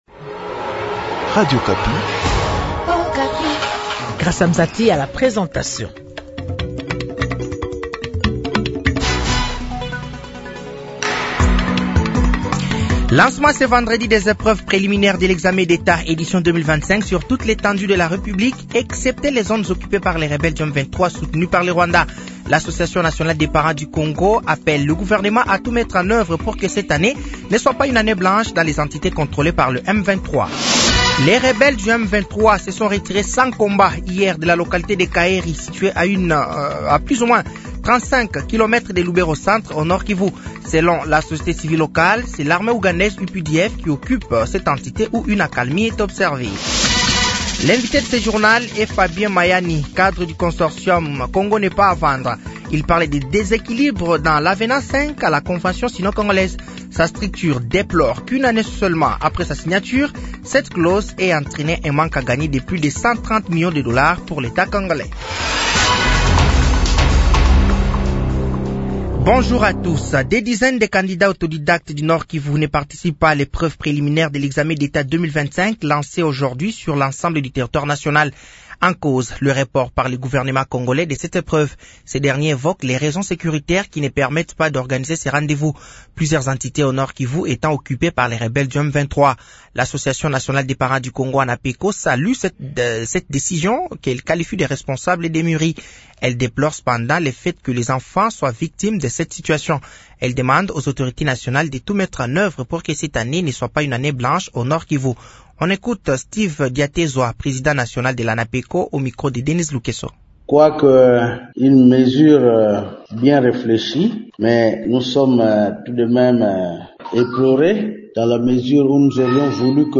Journal français de 15h de ce vendredi 07 mars 2025